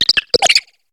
Cri de Picassaut dans Pokémon HOME.